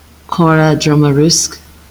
Fuaimniú, Carrick-on-Shannon i mBéarla) príomhbhaile Chontae Liatroma.